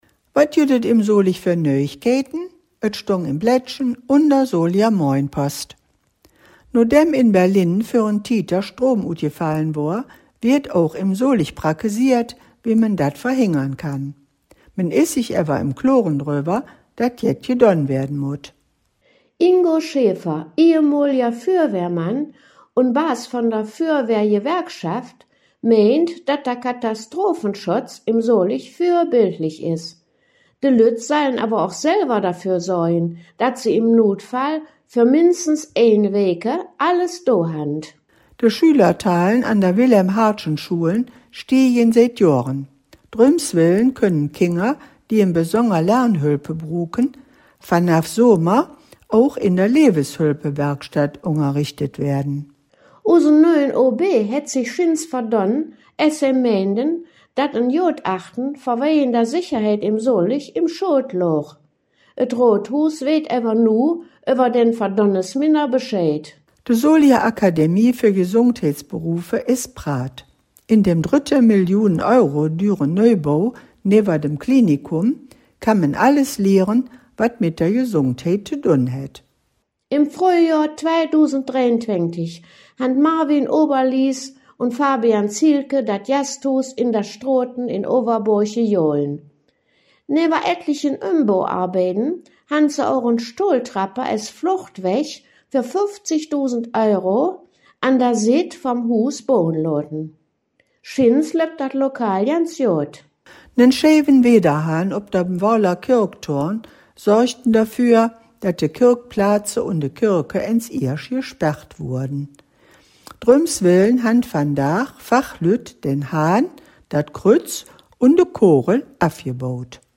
Mitglieder der Solinger Mundartgruppe "De Hangkgeschmedden" blicken in den Nöüegkeïten op Soliger Platt jede Woche für die Studiowelle 2 auf die Ereignisse der vergangenen Tage in der Klingenstadt zurück.